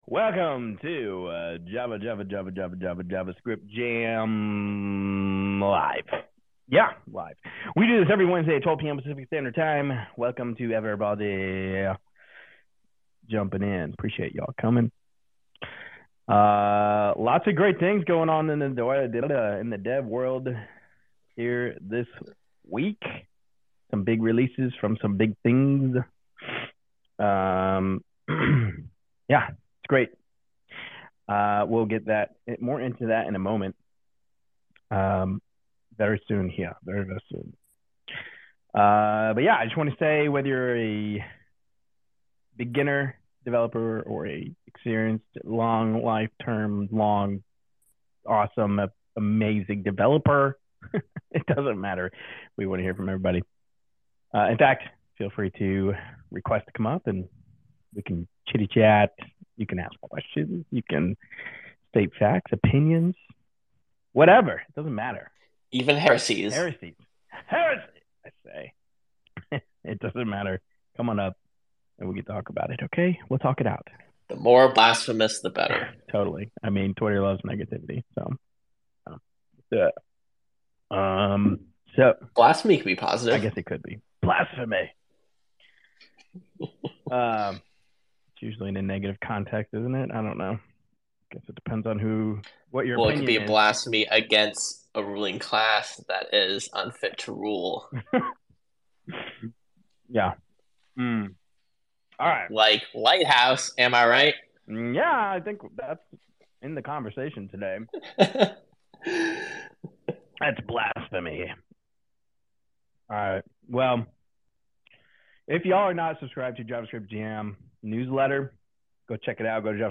Lighthouse, Remix, and Astro updates lead a lively discussion on performance, accessibility, and frameworks in this developer-focused chat